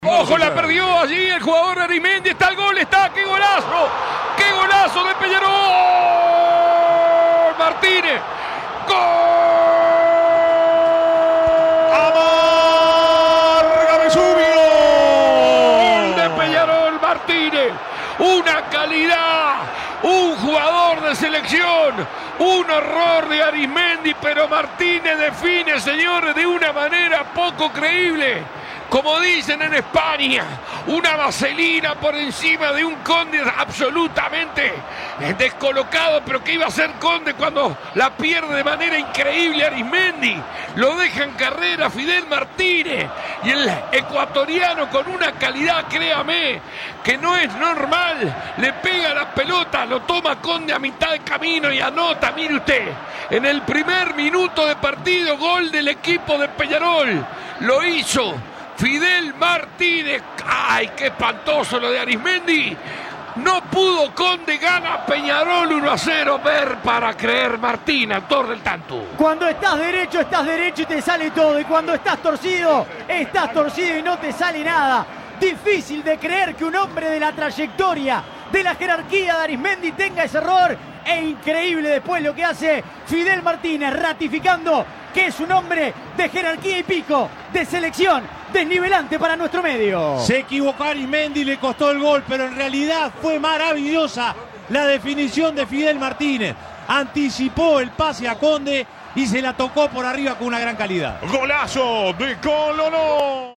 Escuchá los goles relatados por Alberto Sonsol.